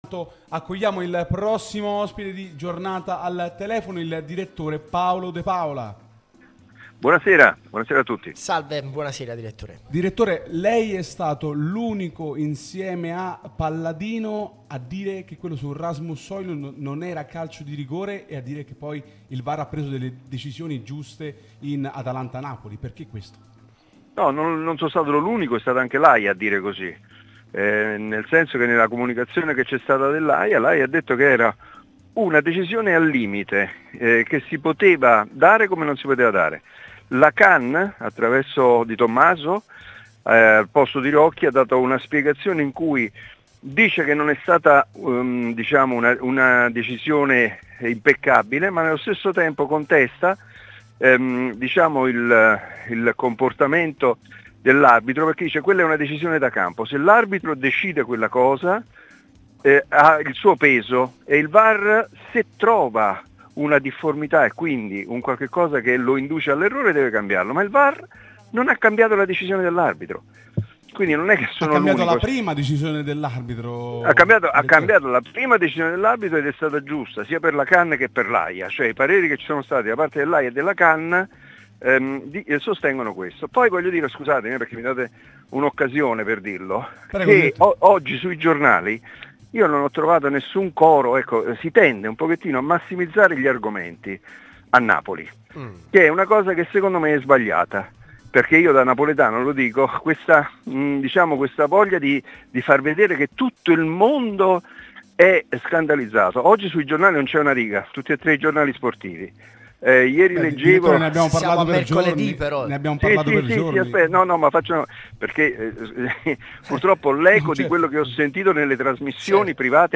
giornalista